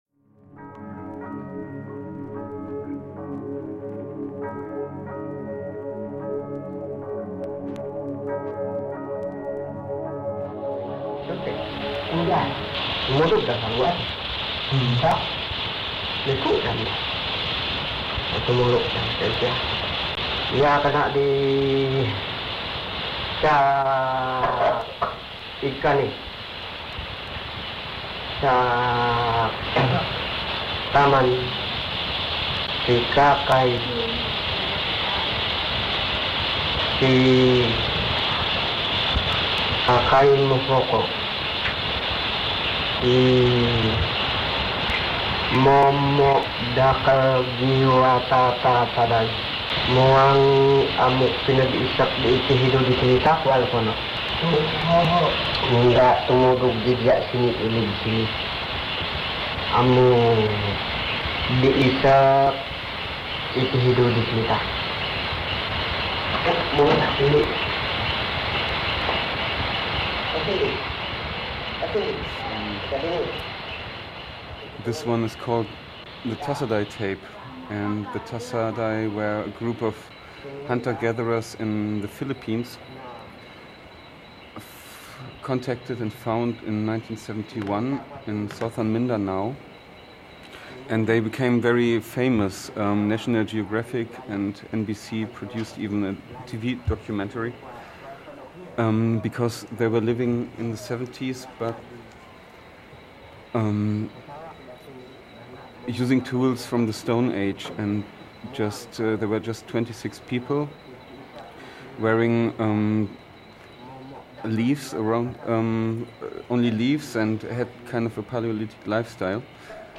TASADAY TAPES* conversation